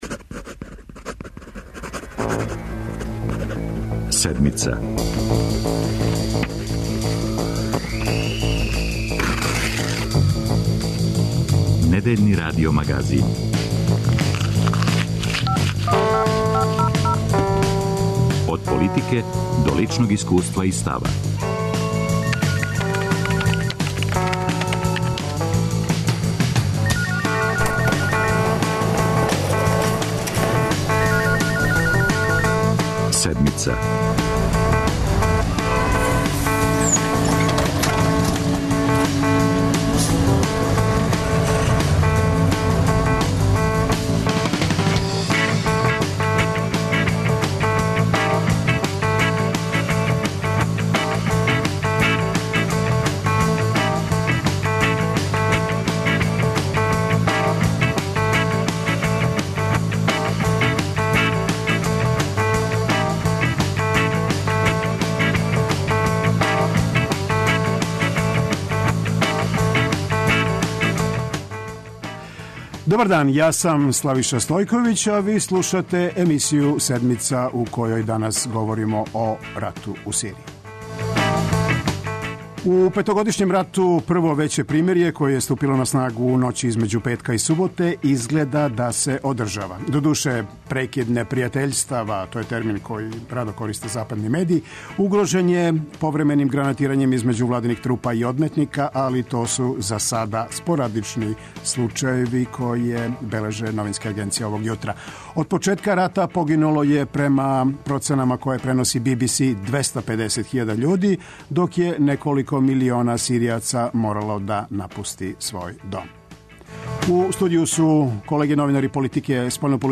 а телефоном ћемо укључити експерте из иностранства и наше спољне дописнике.